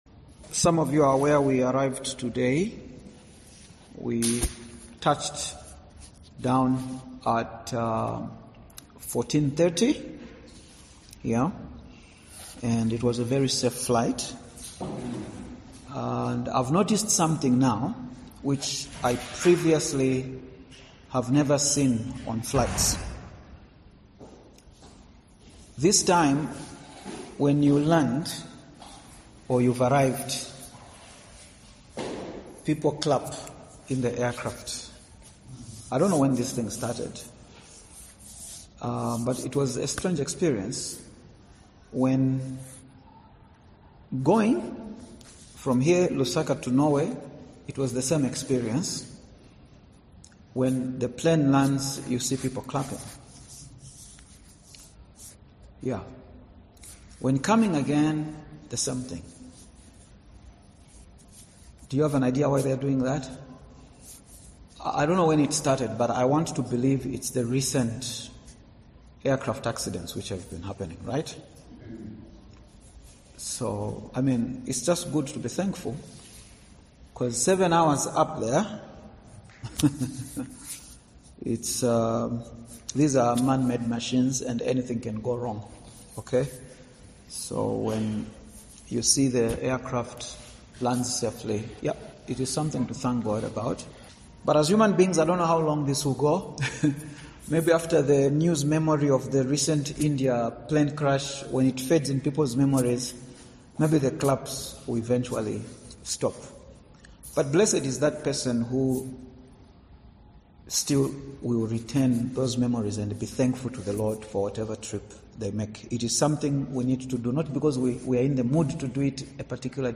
In this short sermon